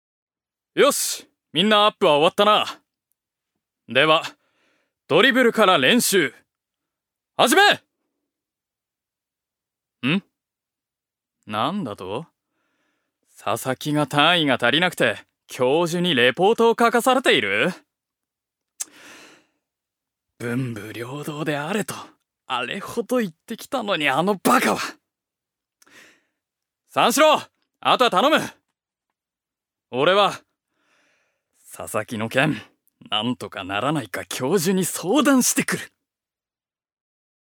所属：男性タレント
セリフ４